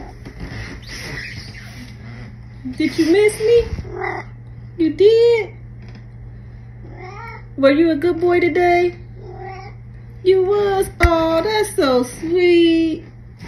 You can hold a conversation with him, and he will answer every one of your questions.
stormyvoice.m4a